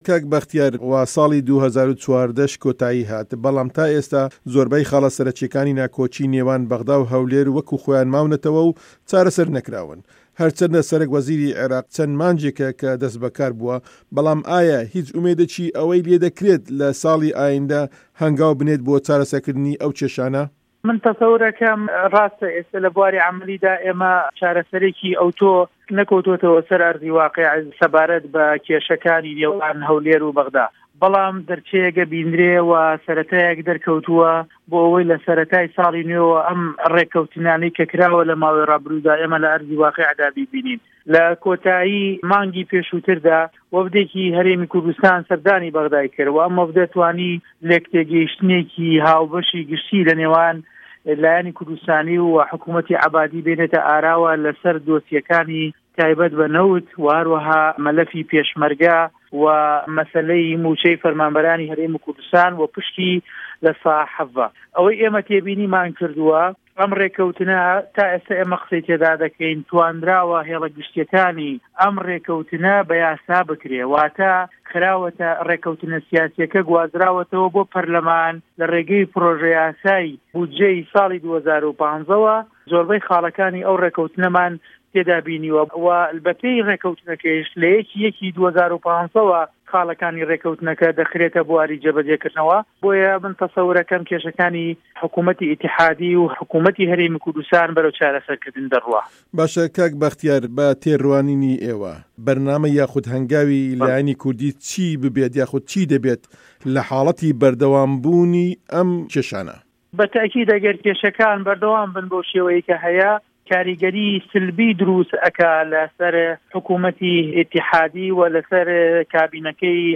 وتووێژی به‌ختیار شاوه‌یس